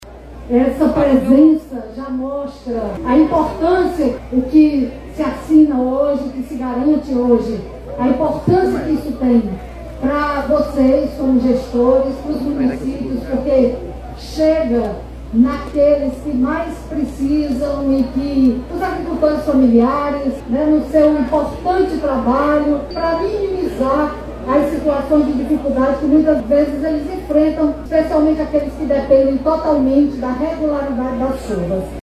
A vice-governadora, Izolda Cela, destacou a importância do seguro Garantia Safra para oferecer aos trabalhadores da agricultura familiar a certeza de uma renda mesmo com um inverno incerto.